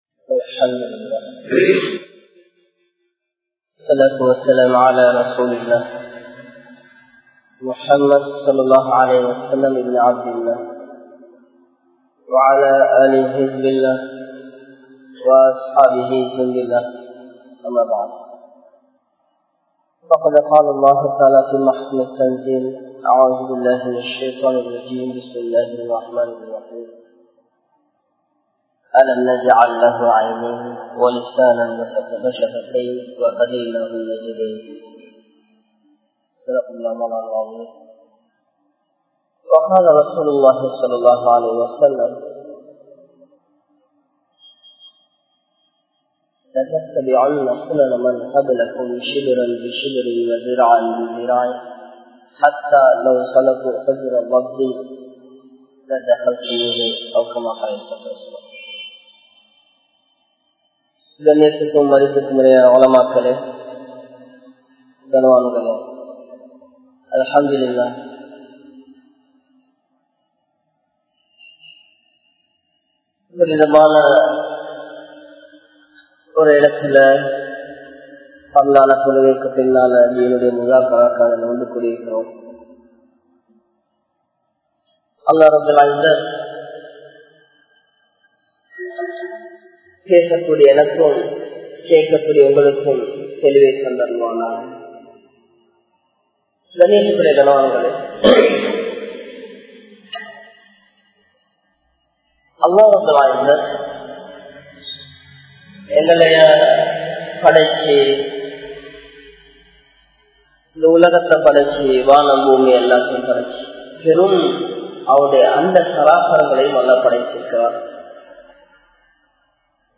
Indraya Vaalifarhalum Tholil Nutpamum (இன்றைய வாலிபர்களும் தொழில்நுட்பமும்) | Audio Bayans | All Ceylon Muslim Youth Community | Addalaichenai